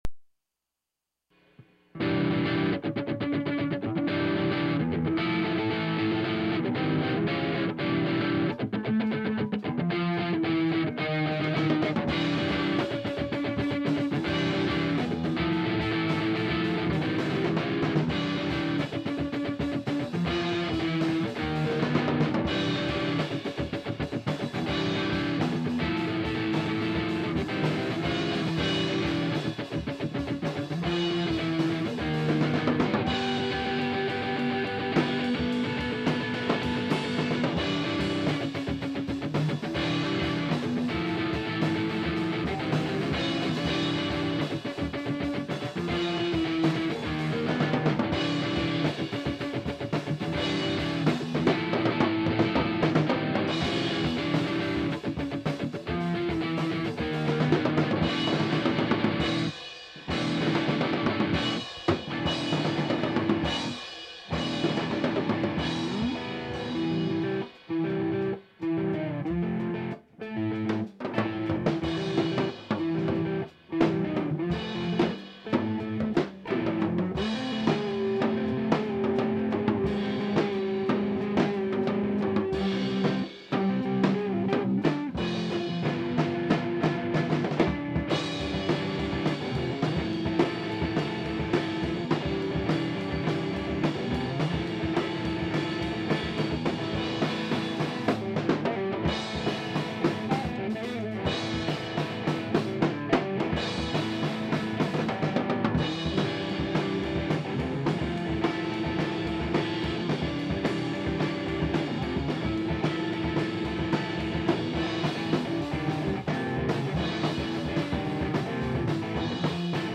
(now in stereo)